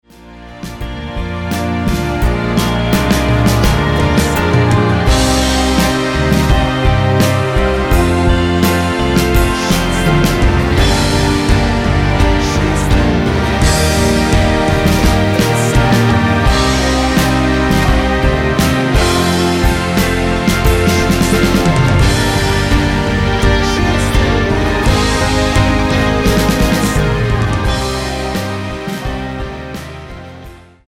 Tonart:Bb mit Chor